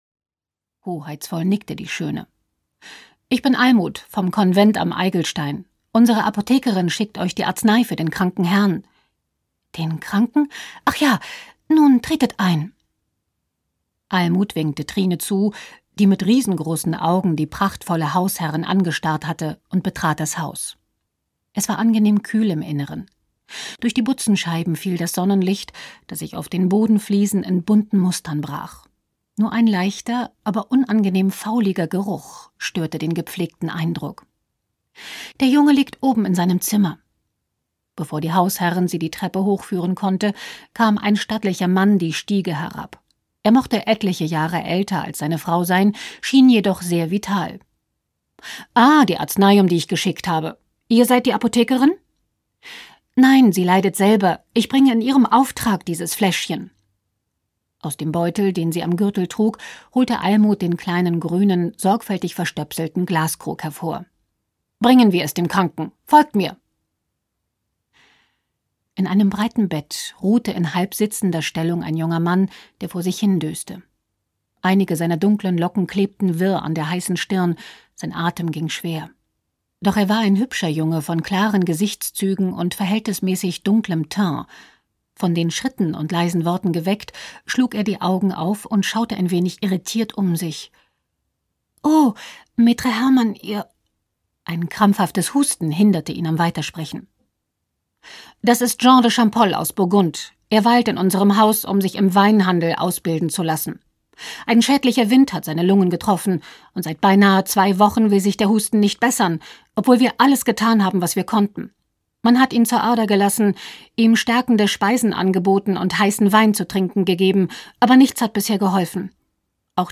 Der dunkle Spiegel - Andrea Schacht - Hörbuch